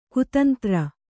♪ kutantra